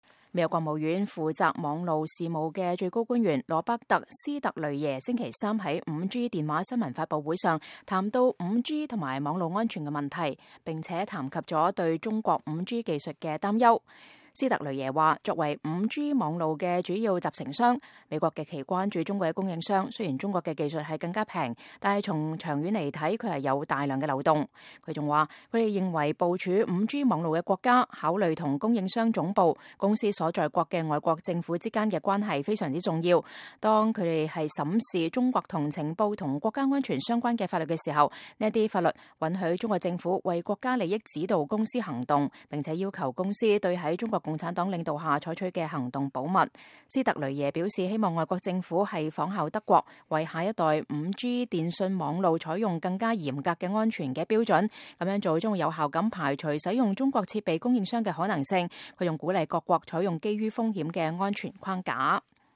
美國國務院負責網路事務的最高官員羅伯特·斯特雷耶週三在5G電話新聞發佈會上談到5G與網路安全問題，並談及了對中國5G技術的擔憂。